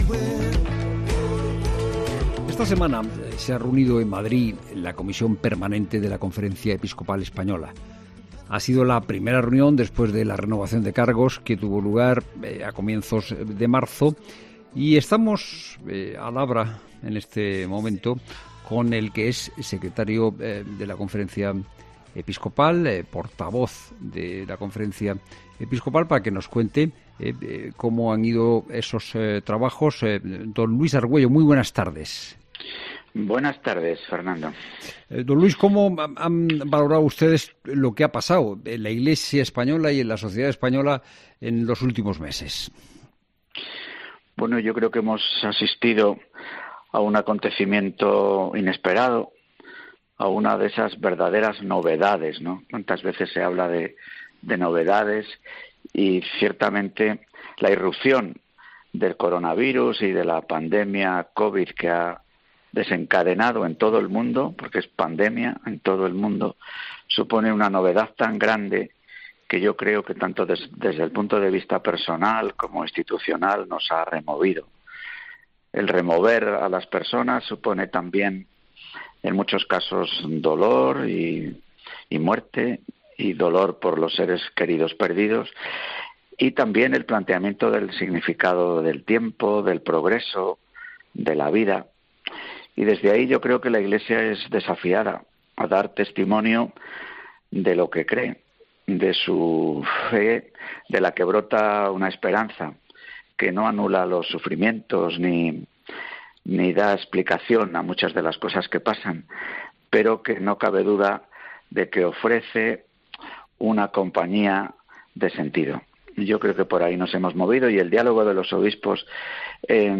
El secretario general y portavoz de la Conferencia Episcopal Española, don Luis Argüello ha pasado por los micrófonos de 'La Tarde' de COPE